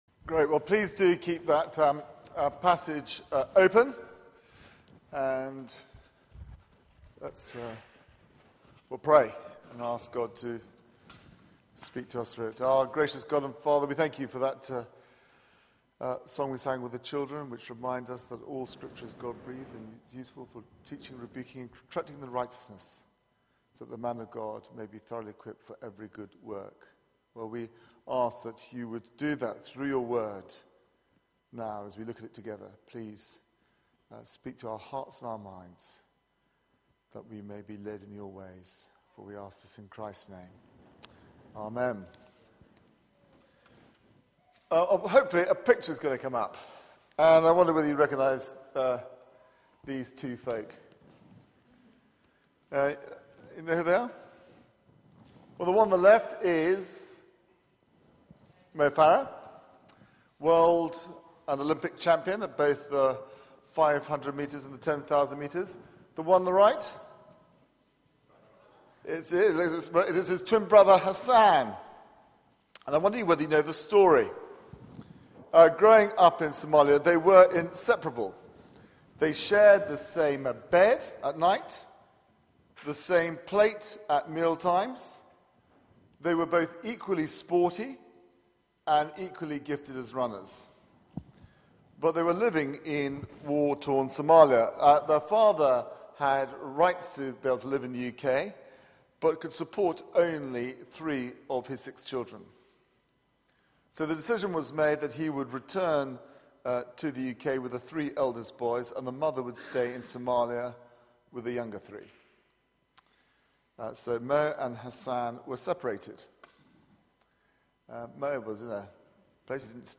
Media for 4pm Service on Sun 06th Oct 2013 16:00 Speaker